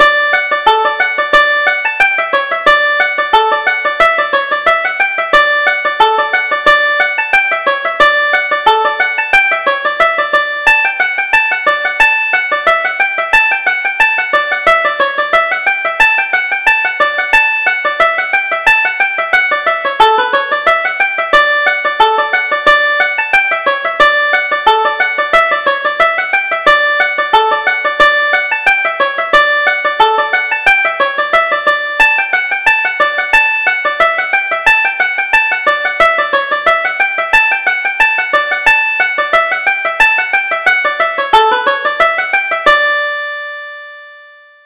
Reel: Green Garters